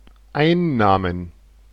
Ääntäminen
US : IPA : [ˈɹɛv.ə.ˌnu] RP : IPA : /ˈɹɛvənjuː/ GenAm: IPA : /ˈɹɛvənju/